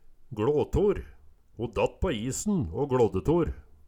gLå tor - Numedalsmål (en-US)